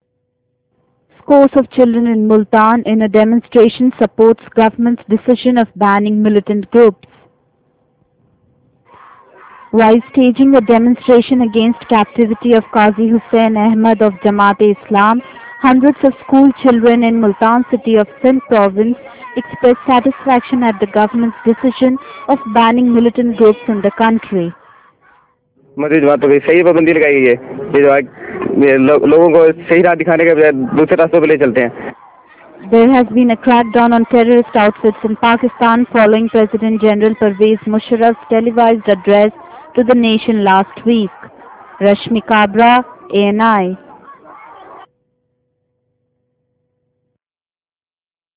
Scores of children in Multan in a demonstration support  government's decision of banning militant groups.